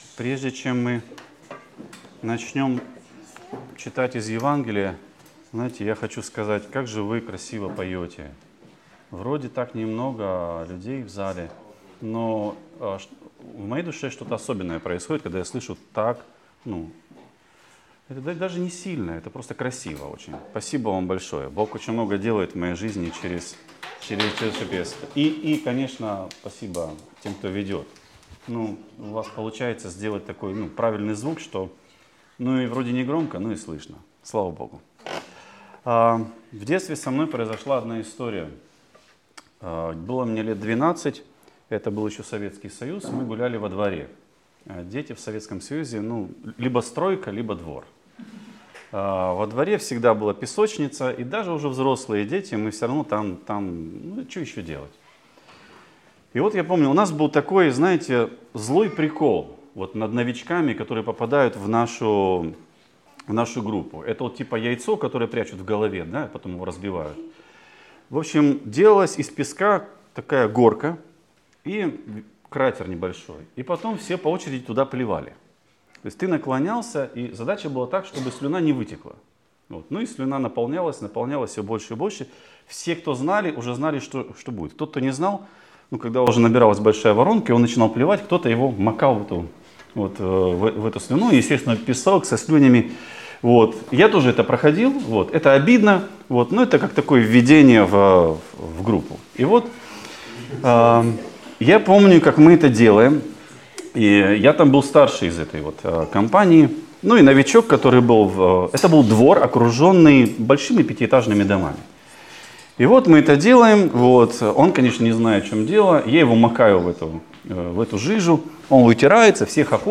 «Преображение» | Церковь евангельских христиан-баптистов